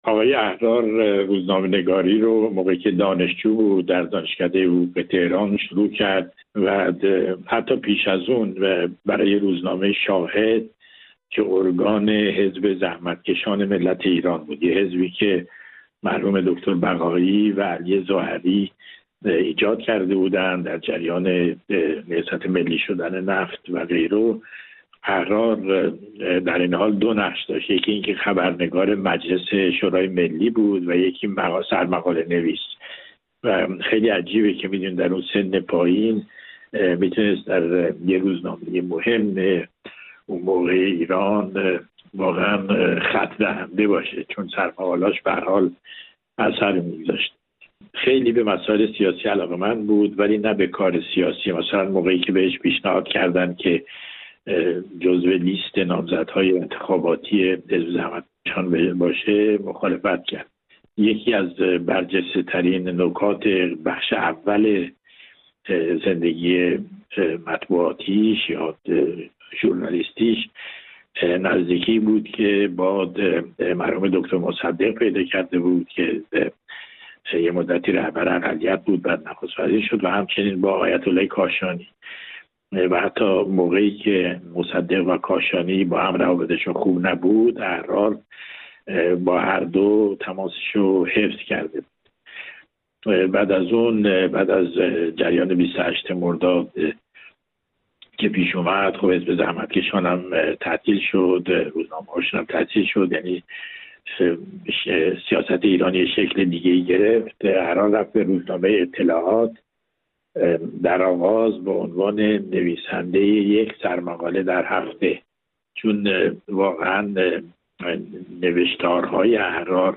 گفت‌وگو با امیر طاهری در مورد کارنامه حرفه‌ای احمد احرار